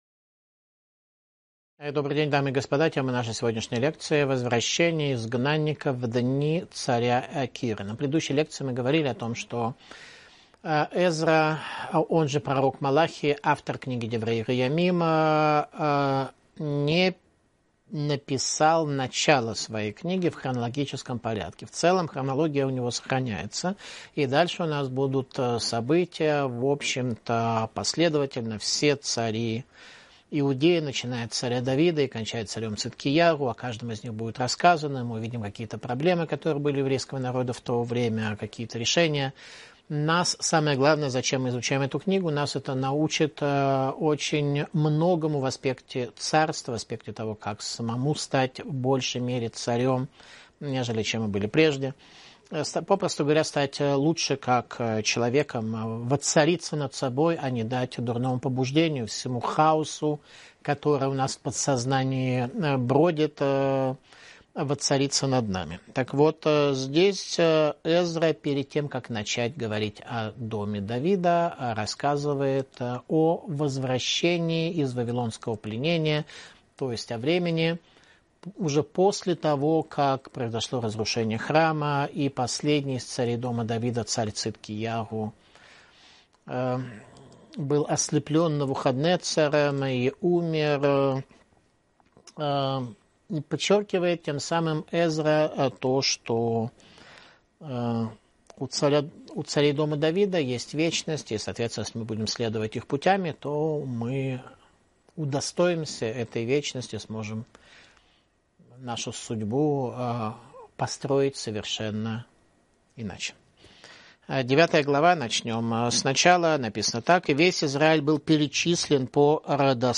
Иудаизм и евреи Видео Видеоуроки Танах Диврей Аямим — Летописи царей Израиля 61.